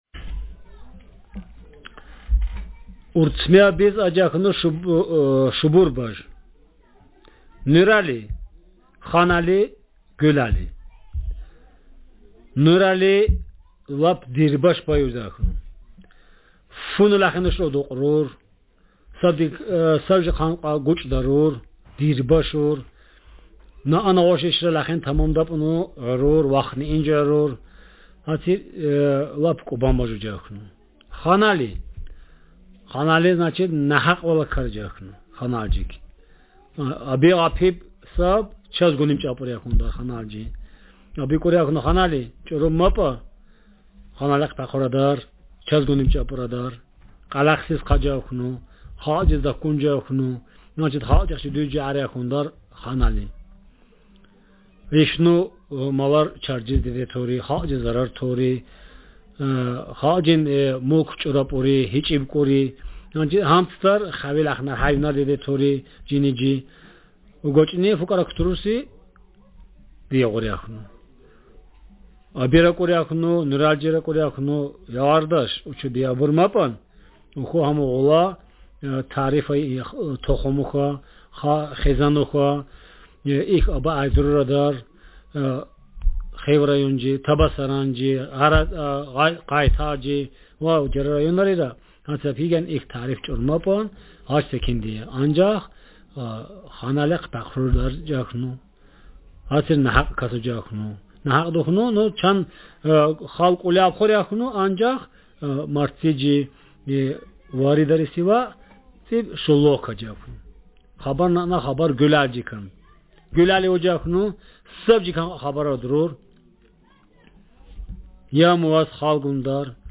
Speaker sexm
Text genretraditional narrative